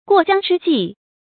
過江之鯽 注音： ㄍㄨㄛˋ ㄐㄧㄤ ㄓㄧ ㄐㄧˋ 讀音讀法： 意思解釋： 比喻某種時興的事物多得很。